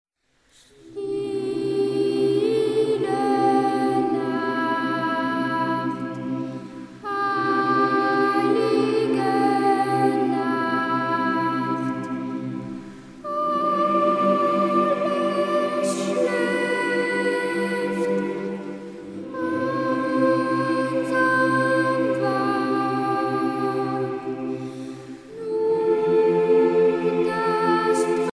Genre strophique Artiste de l'album Psalette (Maîtrise)
Pièce musicale éditée